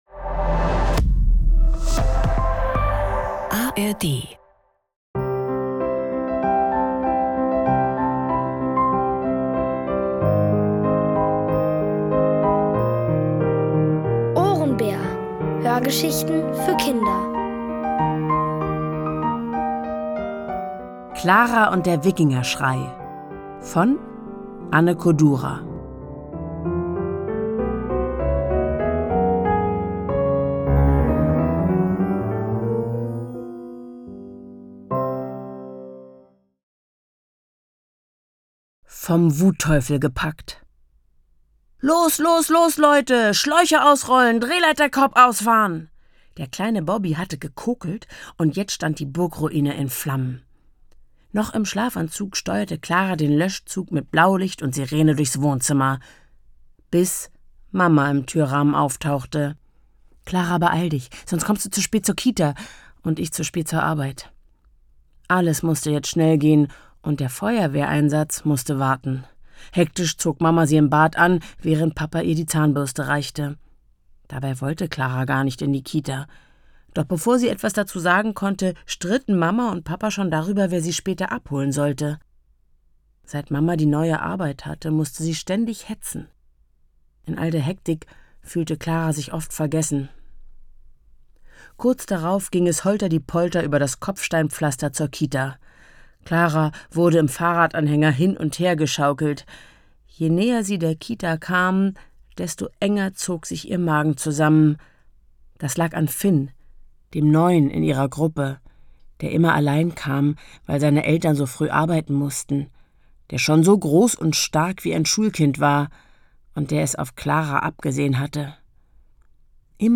Alle 3 Folgen der OHRENBÄR-Hörgeschichte: Klara und der Wikingerschrei von Anne Kodura. Es liest: Helene Grass.